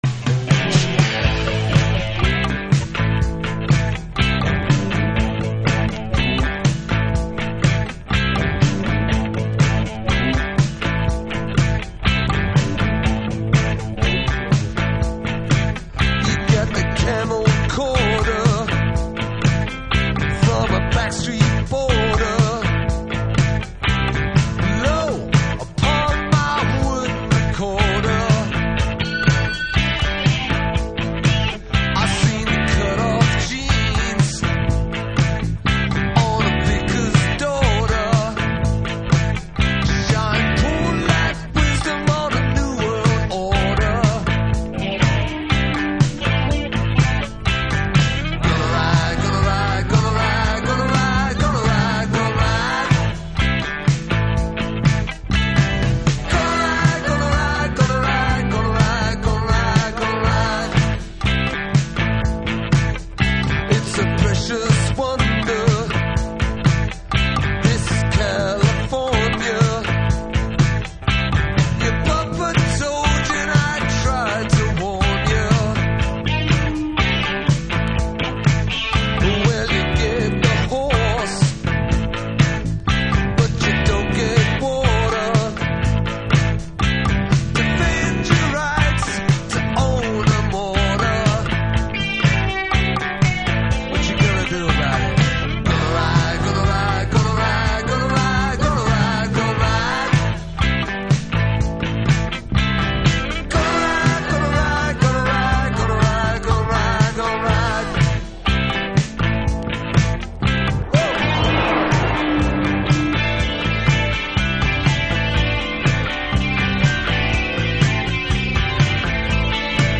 Rock / Prog / Avant
オヤジ遊びが効いたサイケデリックロック。